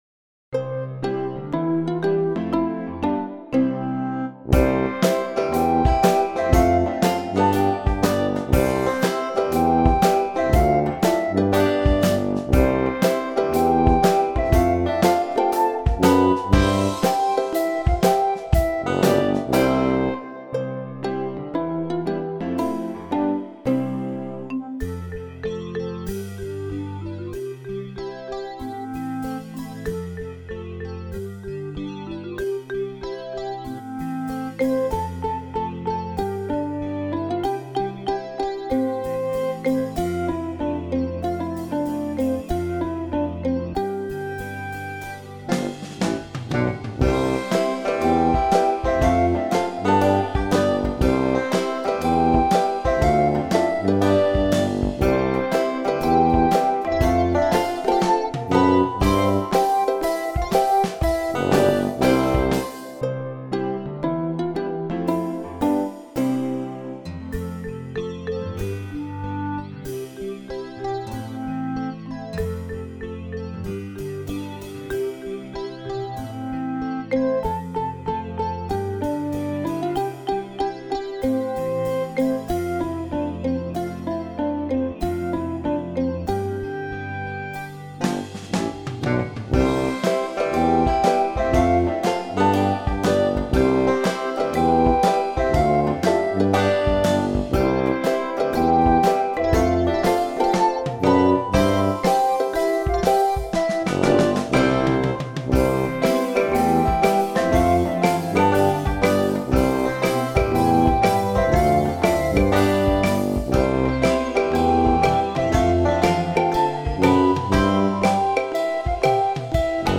Download backing track